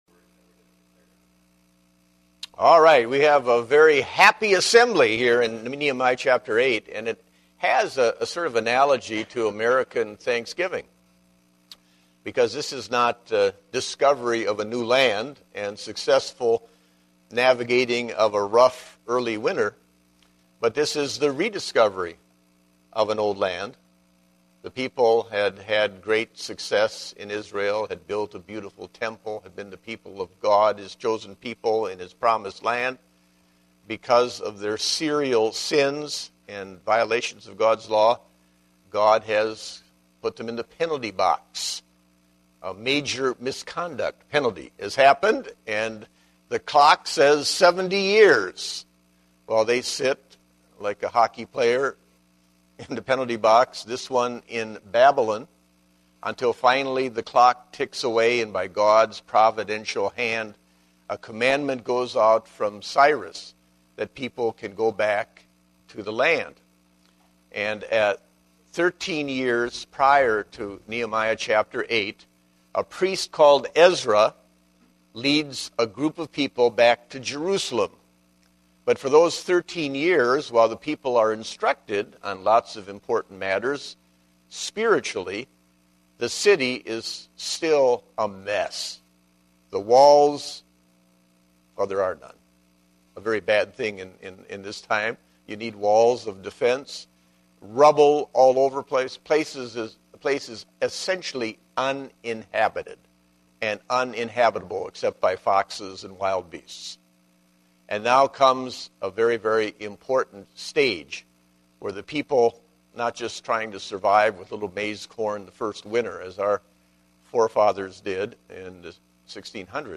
Date: November 21, 2010 (Adult Sunday School)